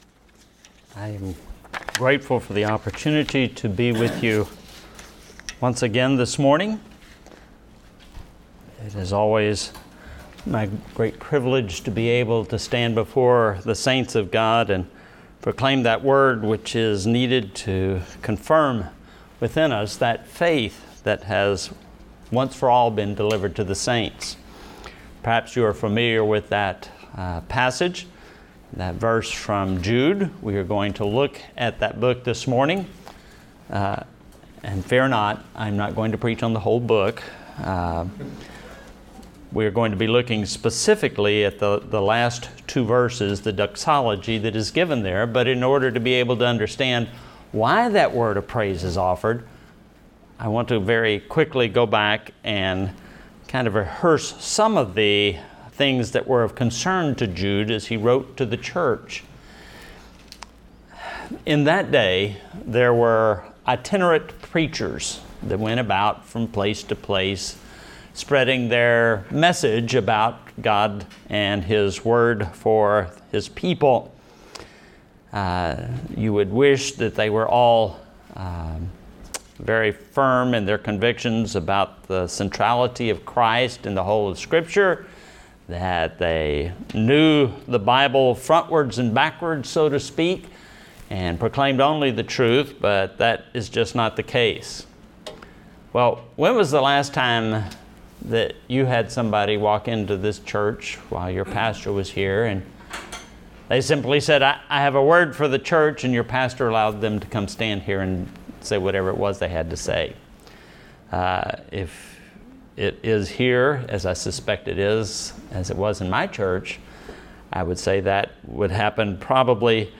Download Audio Home Resources Sermons Praise to God